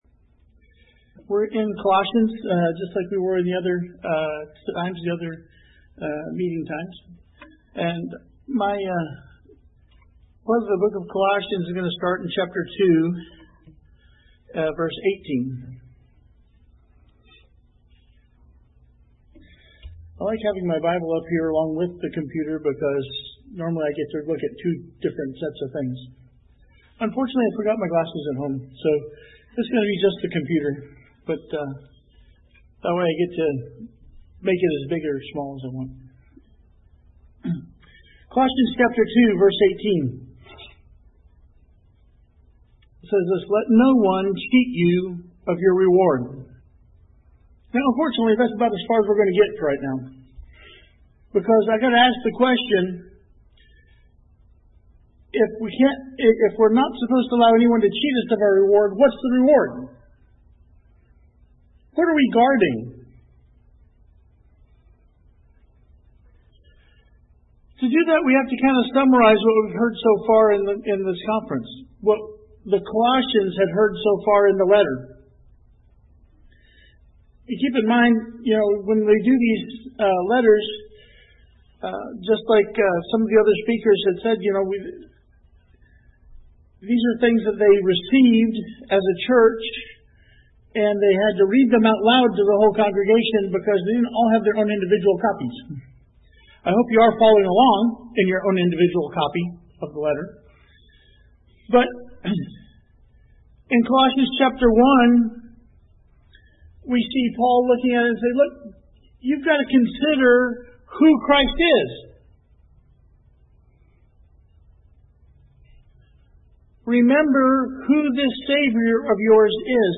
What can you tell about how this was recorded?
Session 5 of the conference, looking at the importance of believers upholding Christ as their Head.